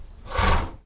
BlowShort22a.wav